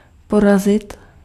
Ääntäminen
IPA: /pɔrazɪt/